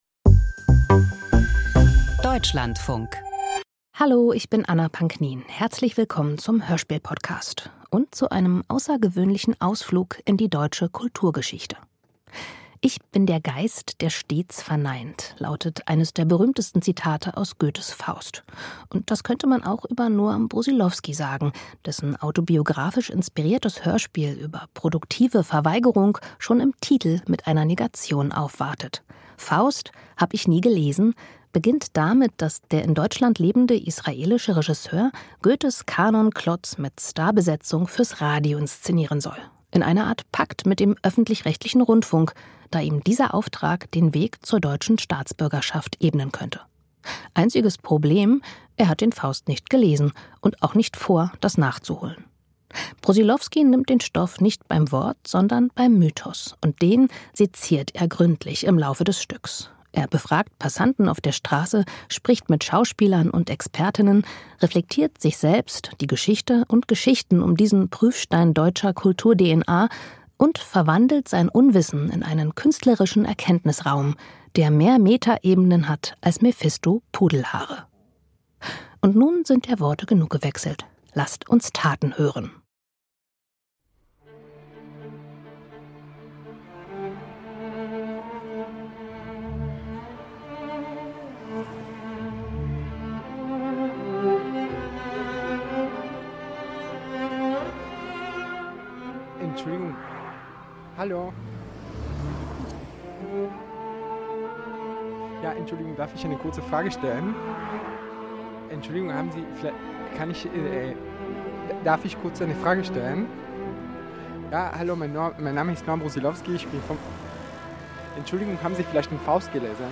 Hörspiel des Monats November 2022 Faust (hab' ich nie gelesen) 76:32 Minuten Hörspielkomödie über Goethes "Faust" und die Mühen des Deutschwerdens.